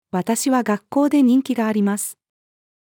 私は学校で人気があります。-female.mp3